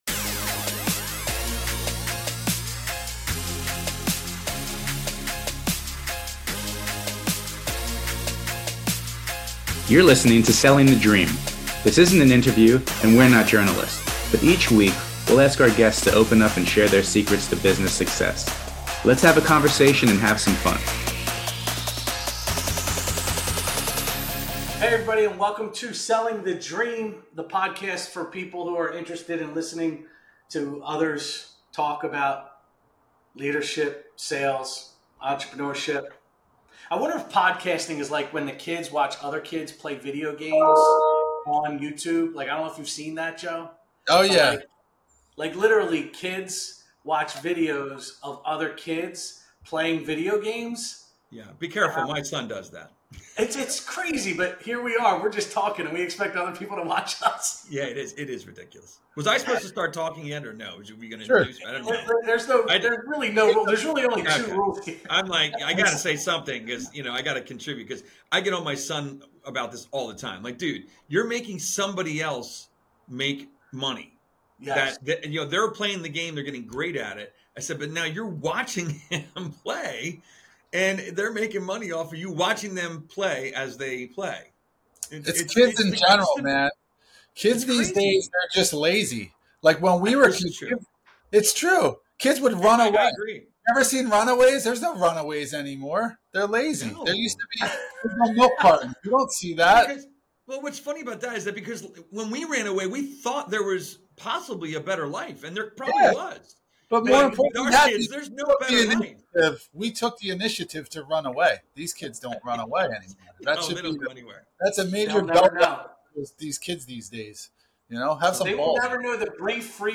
It's a conversation, not an interview, and we have fun exploring this question together with a guest.